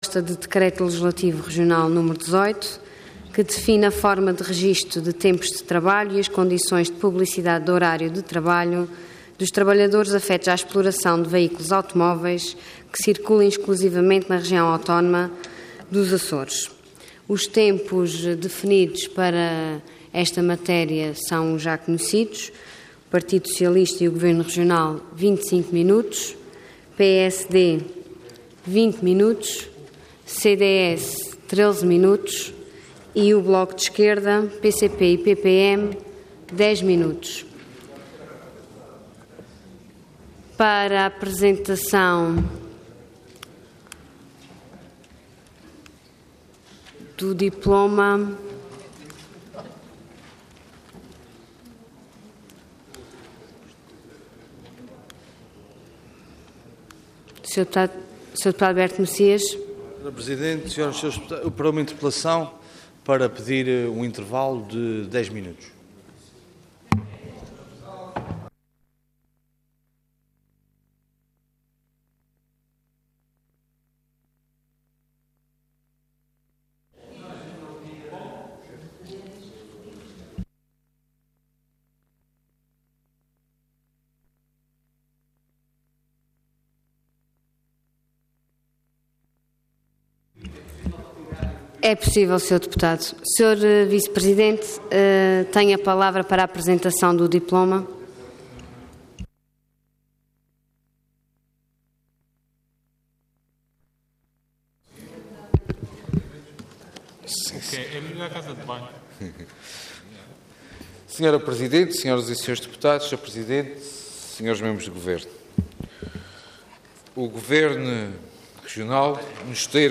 Intervenção Proposta de Decreto Leg. Orador Sérgio Ávila Cargo Vice-Presidente do Governo Regional Entidade Governo